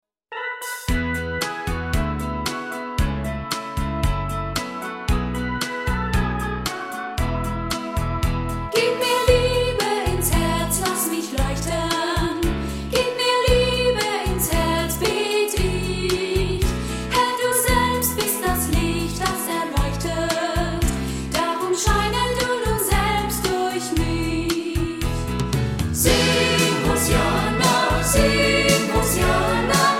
Die schönsten Gemeindelieder
• Sachgebiet: Chormusik/Evangeliumslieder
Neuere Gemeindelieder 0,99 €